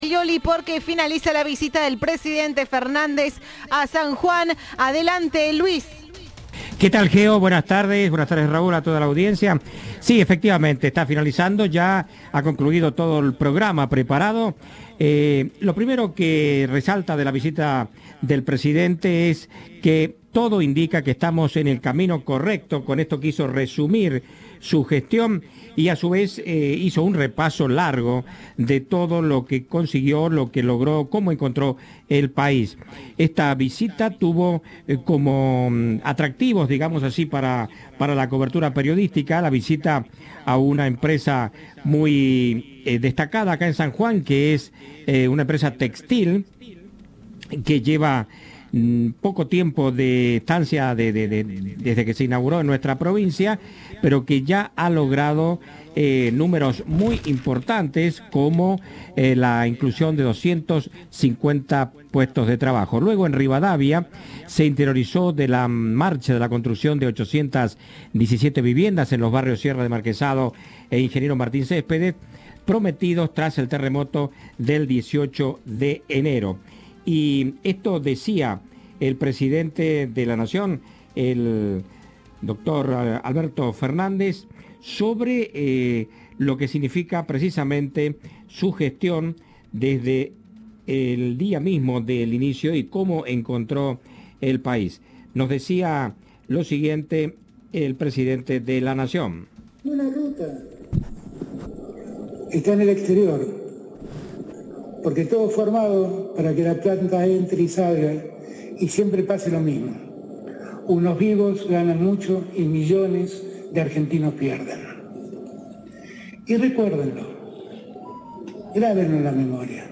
El presidente encabezó este lunes por la tarde un acto de campaña del Frente de Todos, en San Juan, en compañía del gobernador provincial, Sergio Uñac, ministros nacionales y precandidatos locales, donde se mostró convencido de que la Argentina avanza hacia la "puerta de salida" de la pandemia, a partir del plan nacional de vacunación.
"Un mal resultado económico podemos revertirlo, pero una muerte no la podemos revertir", afirmó el Presidente en el acto desarrollado en la Quinta Nazareno, en la localidad sanjuanina de Pocito, del que también participaron los ministros de Desarrollo Productivo, Matías Kulfas, y de Desarrollo Territorial y Hábitat, Jorge Ferraresi.